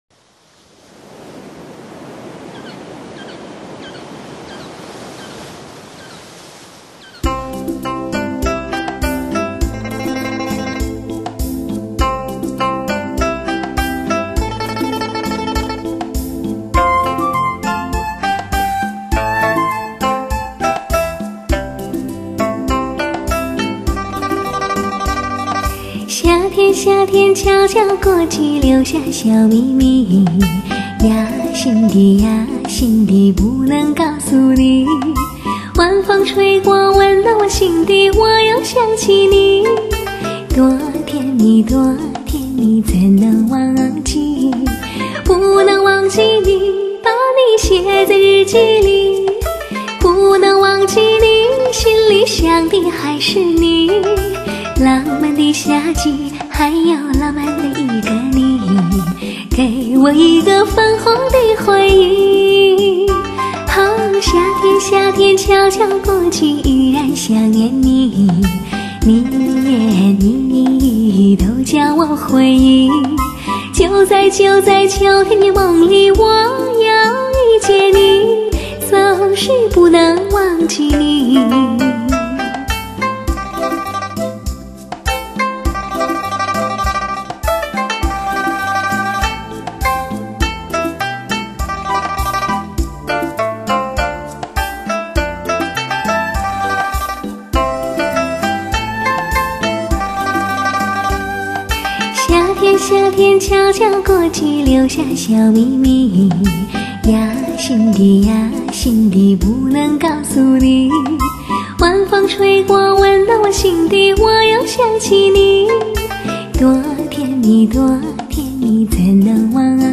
典雅稳重气质  至纯原音魅力  韵味尽显  一种诗化的声音  波动感心灵深处的弦